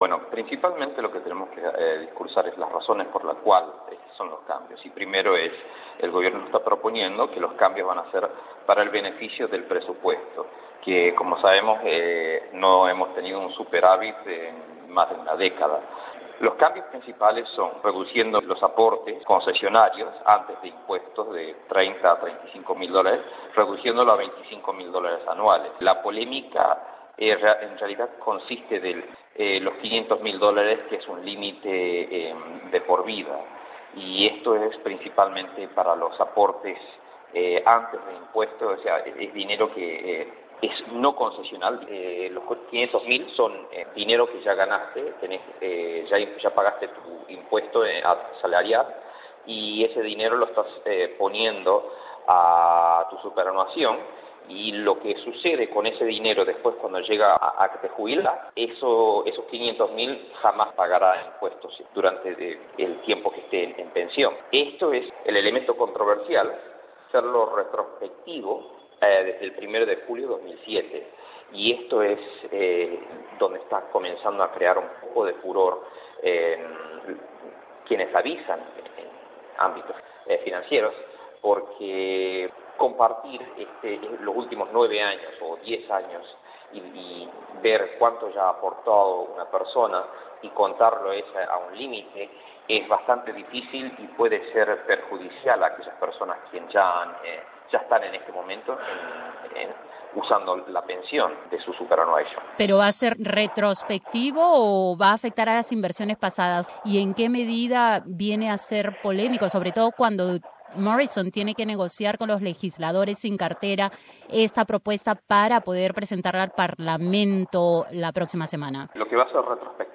y comenzó la entrevista refiriéndose precisamente a lo más polémico de la propuesta Share